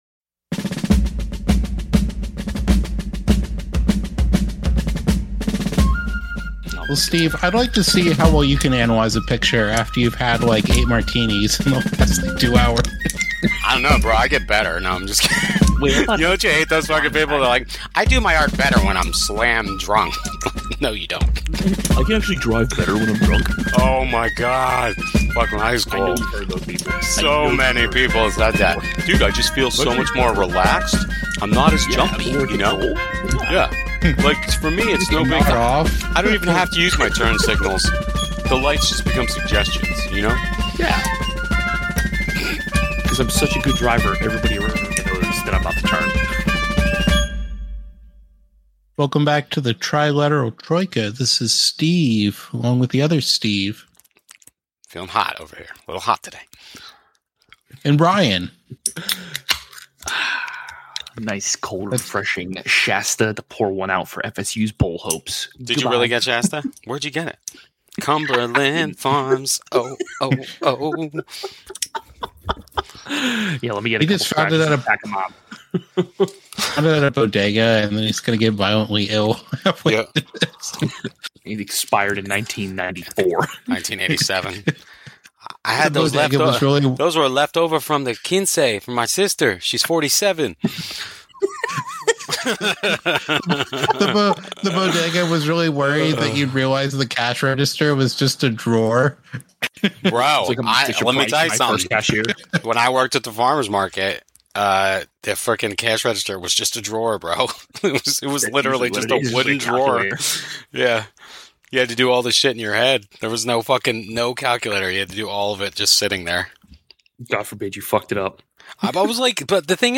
Disclaimer: We are three inebriated dudes(mostly) talking about history(mostly).
We may make a mistake or two, and many more bad jokes, but it's all for fun.